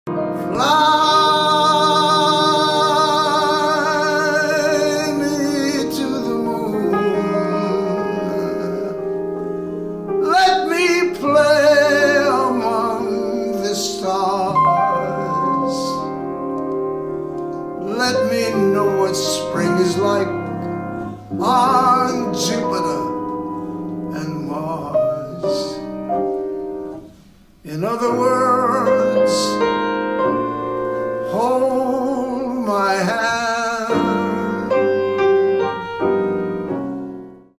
performed right from my living room in 2020